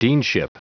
Prononciation du mot deanship en anglais (fichier audio)
Prononciation du mot : deanship